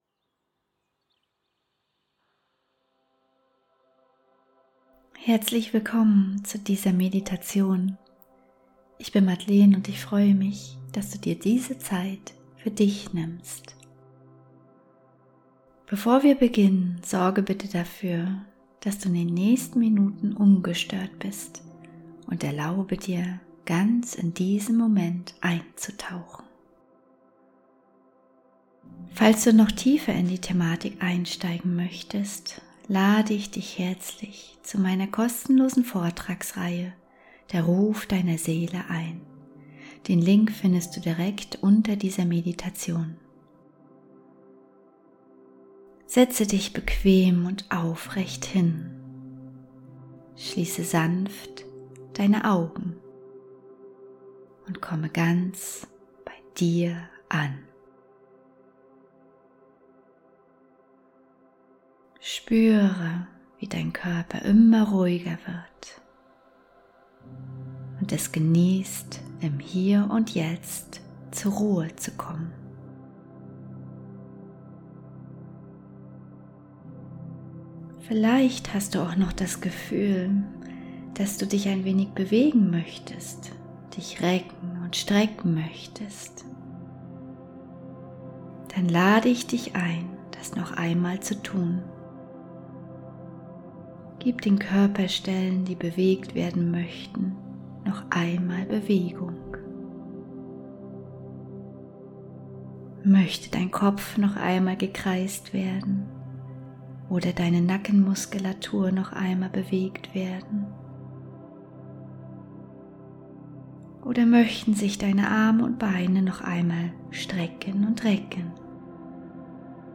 Willkommen zu dieser 15-minütigen geführten Meditation für Präsenz im Hier und Jetzt. In dieser intimen Meditation nehme ich dich an die Hand und begleite dich sanft aus dem Gedanken-Chaos in die innere Stille. Du lernst, deinen Körper bewusst wahrzunehmen, deine Gefühle zu beobachten ohne daran festzuhalten, und Gedanken wie Wellen kommen und gehen zu lassen.